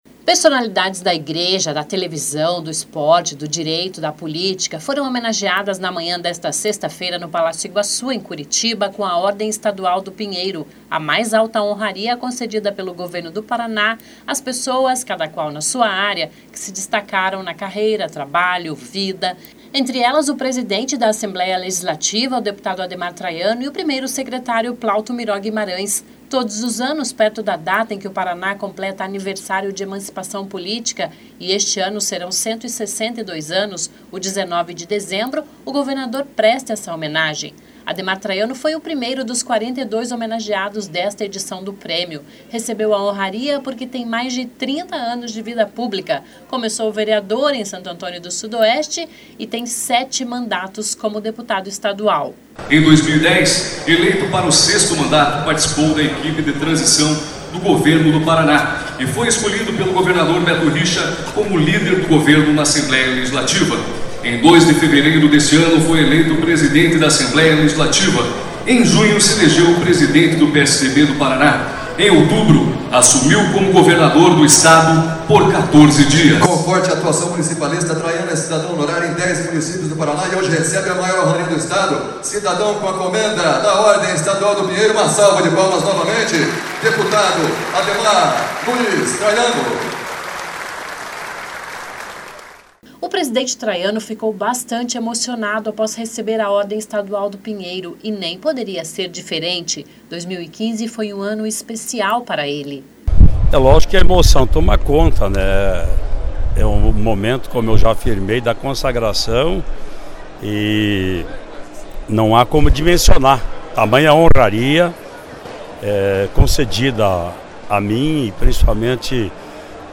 Personalidades da igreja, da televisão, do  esporte, do direito, da política,  foram homenageadas  na manhã desta sexta (18), no Palácio Iguaçu, em Curitiba,  com a Ordem Estadual do Pinheiro, a mais alta honraria concedida pelo Governo do Paraná às pessoas, cada qual na sua área, que  se destacaram  na carreira,  trabalho e na vida.
(sobe som)
(Sonora)
(Sobe som/Beto Richa)